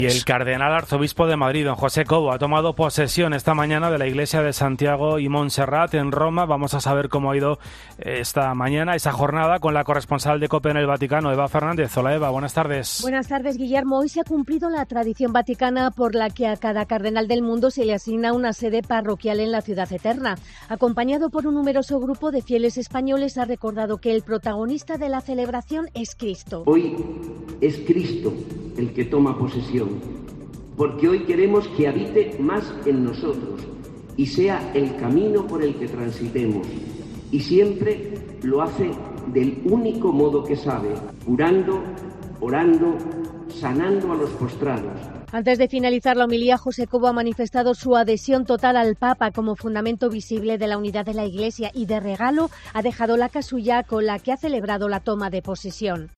Se trata de la iglesia Santa María de Montserrat, la iglesia de los españoles en Roma.
En su homilía, agradecía al Papa Francisco su confianza en él para este nombramiento.